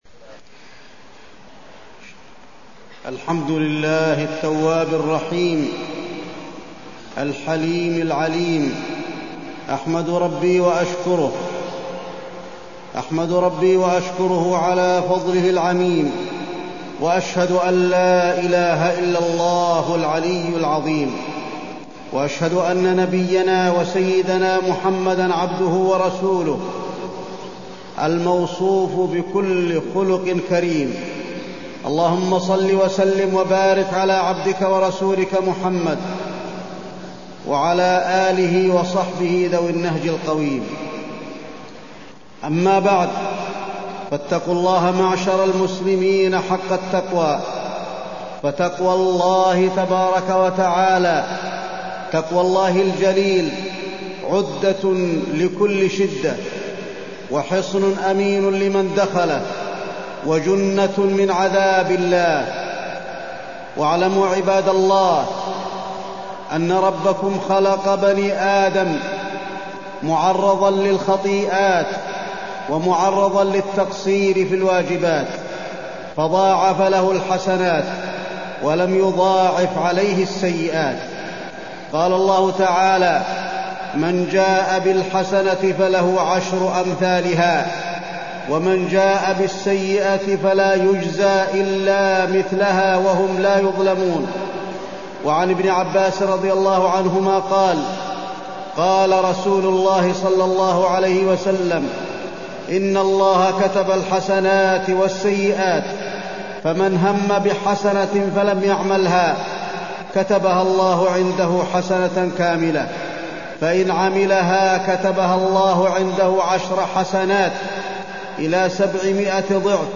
تاريخ النشر ٣ جمادى الآخرة ١٤٢٤ هـ المكان: المسجد النبوي الشيخ: فضيلة الشيخ د. علي بن عبدالرحمن الحذيفي فضيلة الشيخ د. علي بن عبدالرحمن الحذيفي التوكل على الله